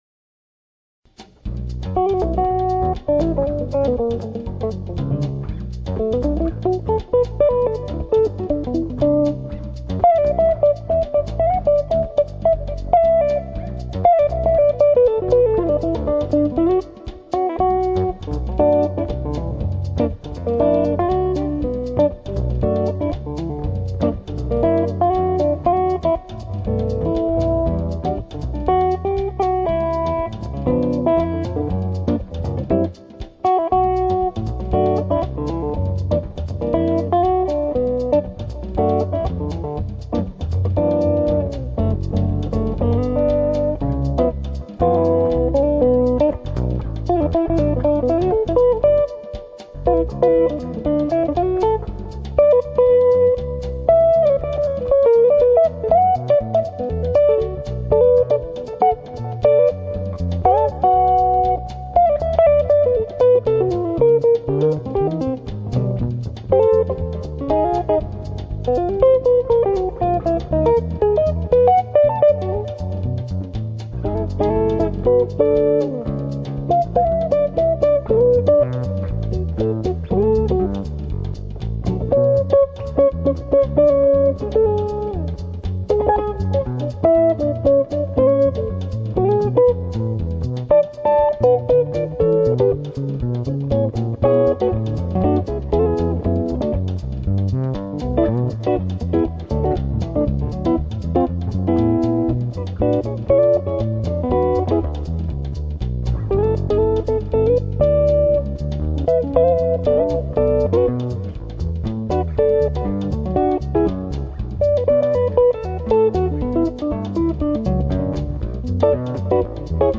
Blues version